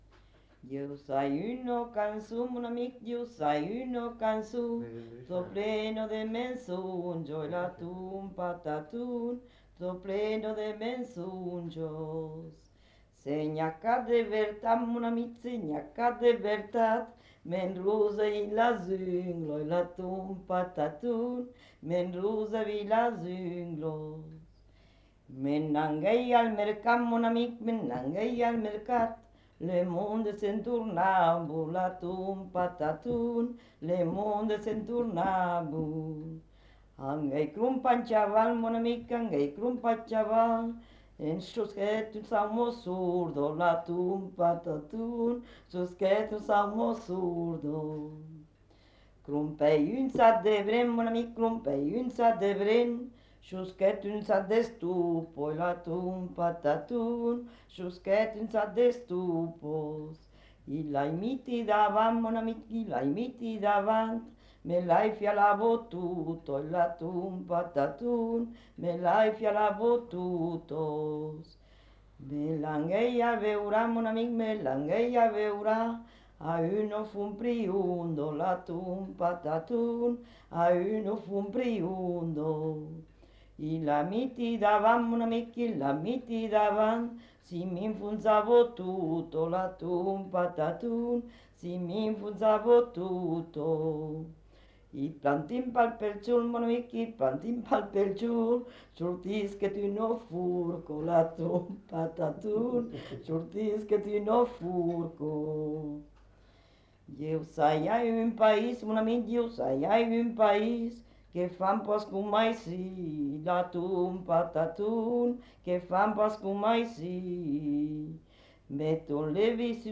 Aire culturelle : Lauragais
Lieu : Lanta
Genre : chant
Type de voix : voix de femme
Production du son : chanté
Classification : chanson de mensonges